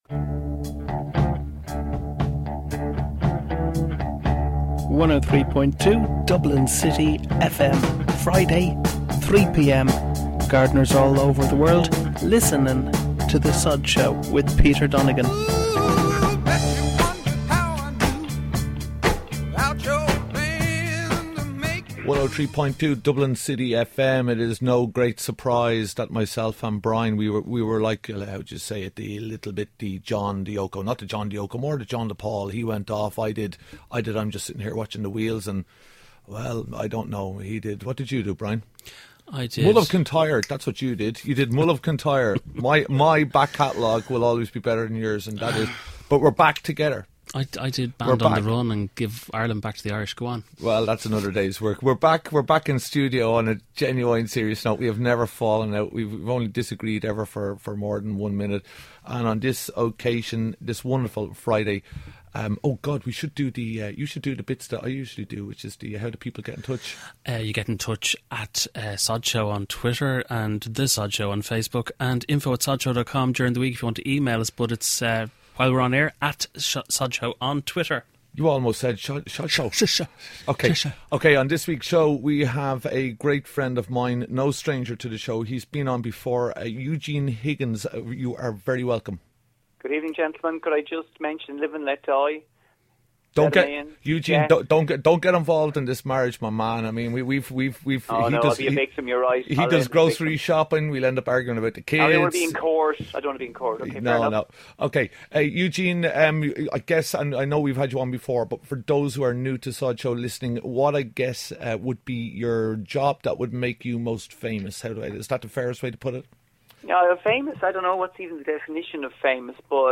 The Sodshow airs every Friday live at 3pm on Dublin City FM and is available in iTunes and all good podcast stores around 3.30pm the same day.